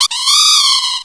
Cri de Lakmécygne dans Pokémon Noir et Blanc.